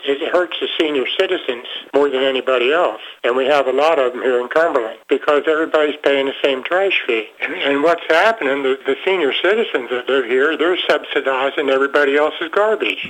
Rates for collection were included in property taxes. Former council member Harvey May would like to see a return to that – he addressed the city council, saying the trash rates are unfair…